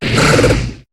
Cri de Feurisson dans Pokémon HOME.